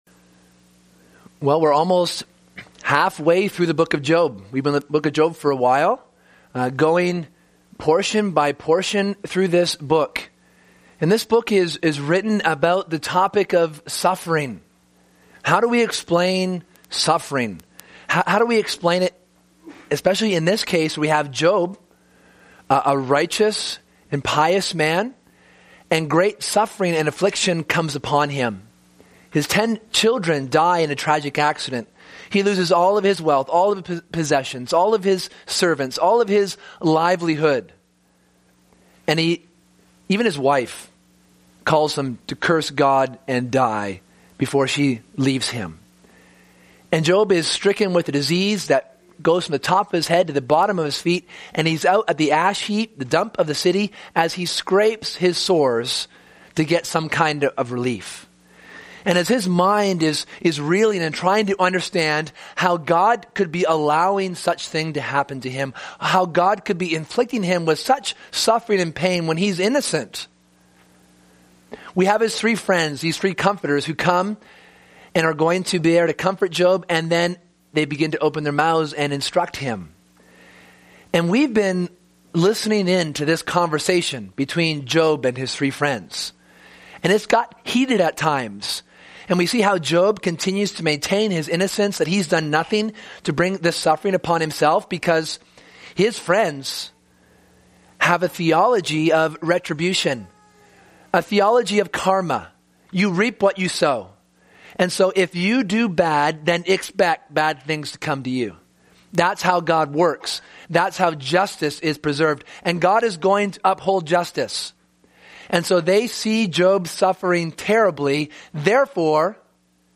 Sermon: Before God's Throne